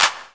CLAP DM2-1.wav